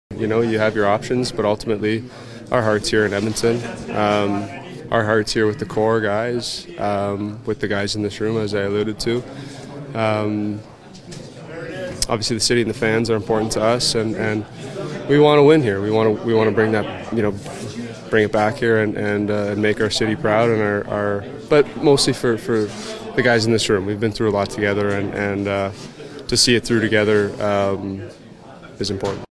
McDavid spoke to reporters on Tuesday